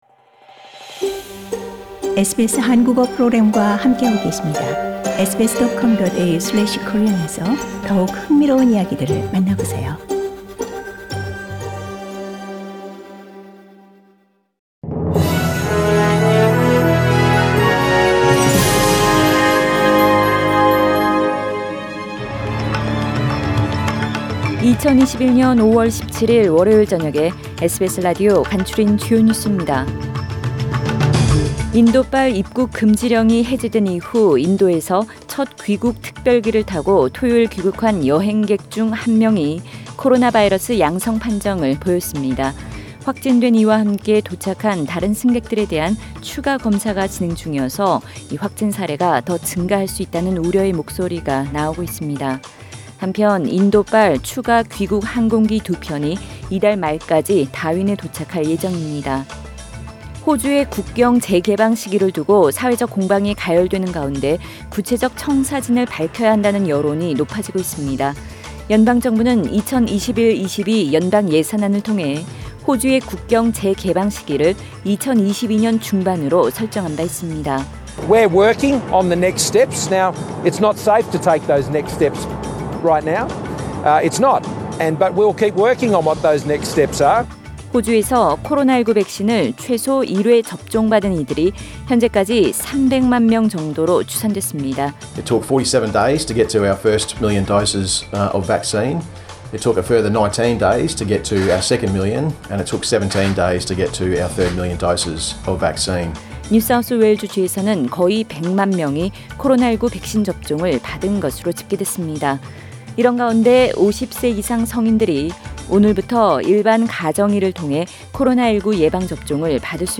2021년 5월 17일 월요일 저녁의 SBS 뉴스 아우트라인입니다.